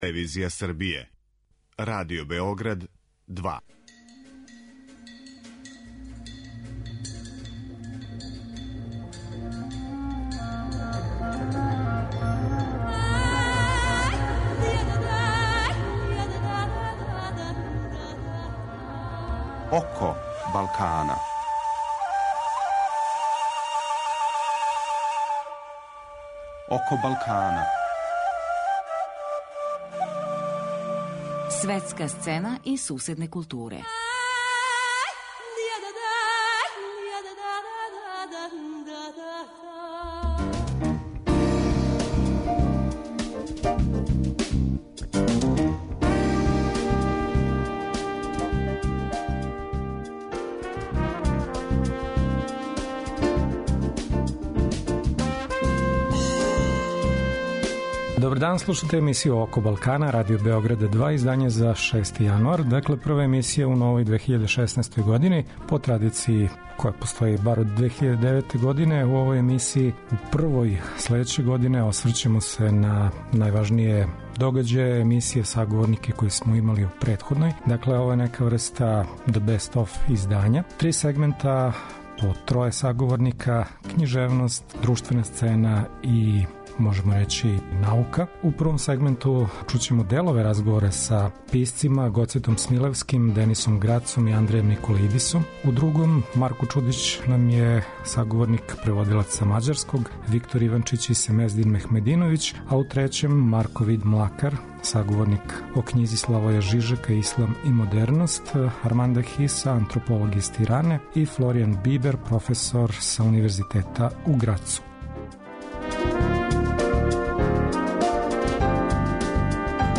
Чућемо делове најзанимљивијих разговора које смо водили током претходне године.